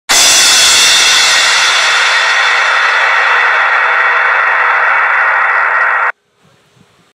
Crash Cymbal Sound Effect Free Download
Crash Cymbal